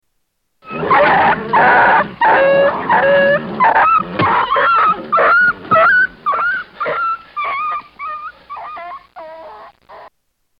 Wounded wolf running off